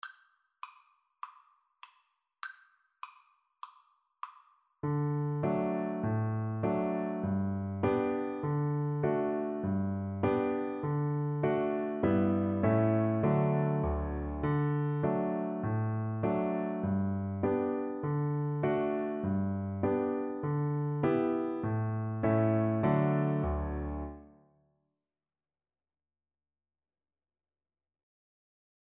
Moderato
4/4 (View more 4/4 Music)